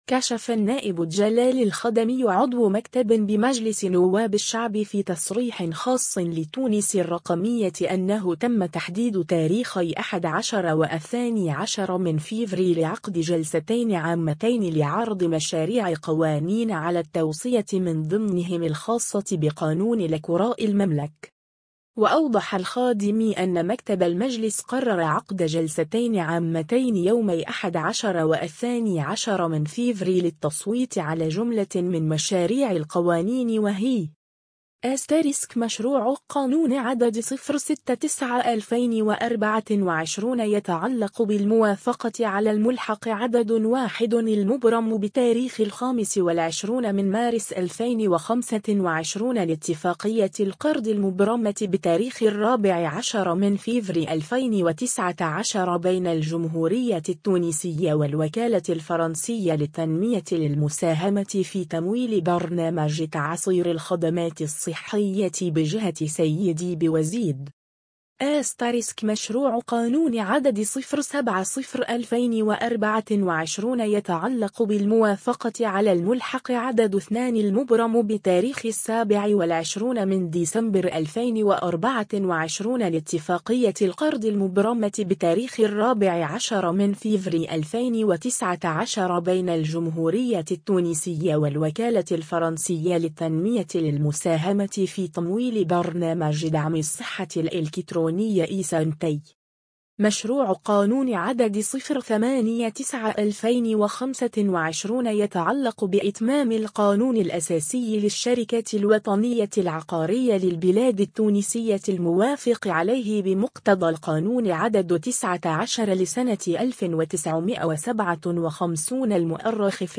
كشف النائب جلال الخدمي عضو مكتب بمجلس نواب الشعب في تصريح خاص لـ”تونس الرقمية” أنه تم تحديد تاريخي 11 و12 فيفري لعقد جلستين عامتين لعرض مشاريع قوانين على التوصيت من ضمنهم الخاصة بقانون الكراء المملك.